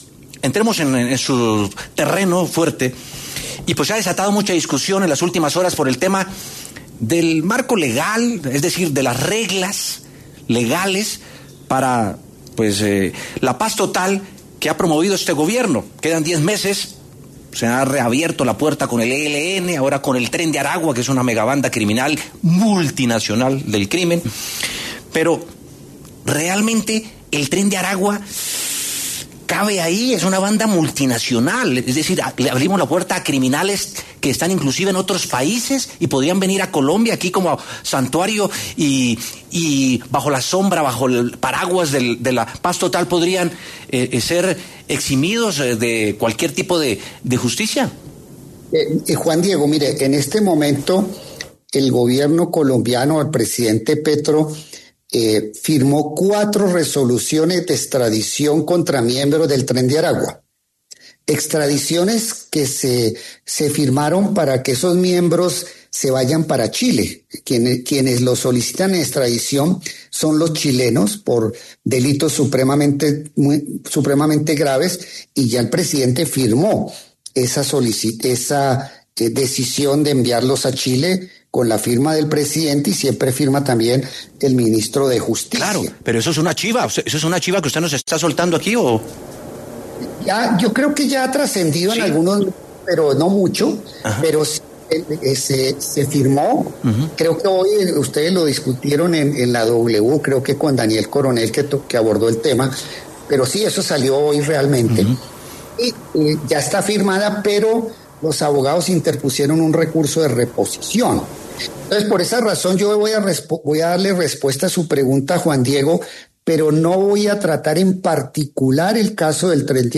En diálogo con W Sin Carreta, el ministro de Justicia, Eduardo Montealegre, se refirió a la discusión en torno al marco legal para la paz total que ha promovido el Gobierno de Gustavo Petro, el ministro Eduardo Montealegre se pronunció sobre la intención del Tren de Aragua de sumarse a esta iniciativa pese a ser una banda multinacional.